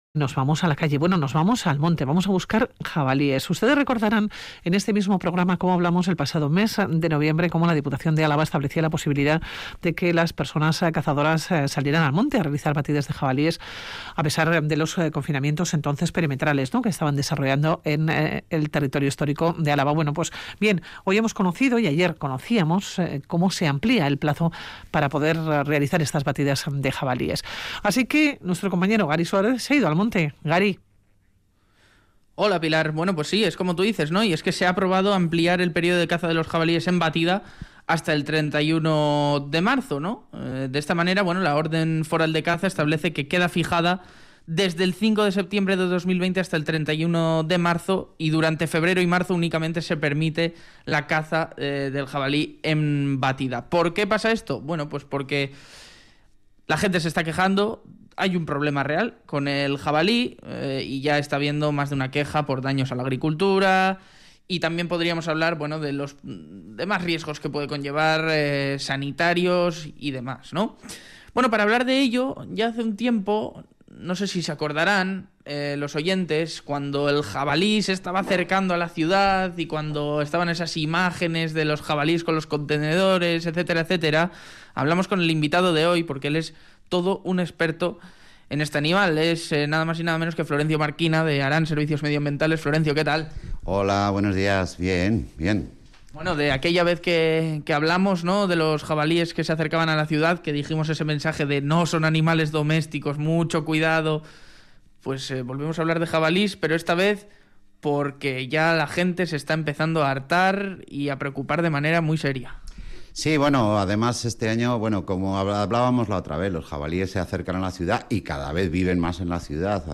Charlamos con el biólogo y experto en jabalís